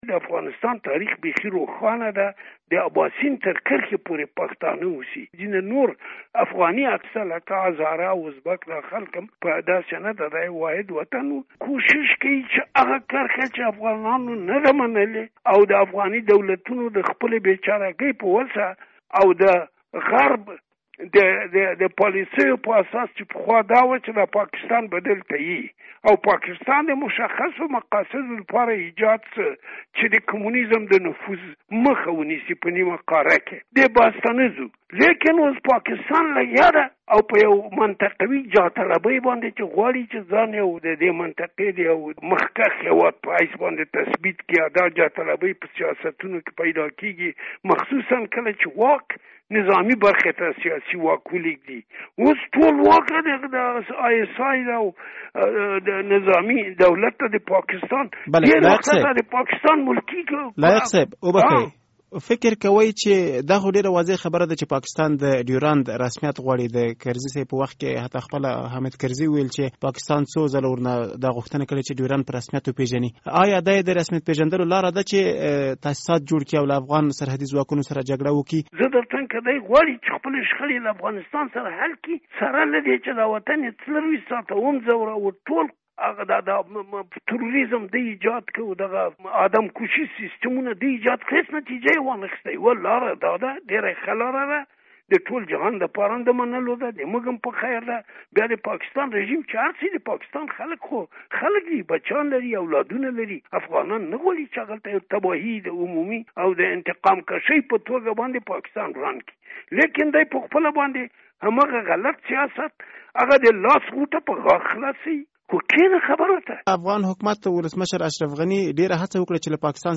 مرکه
له سلمان لایق سره مرکه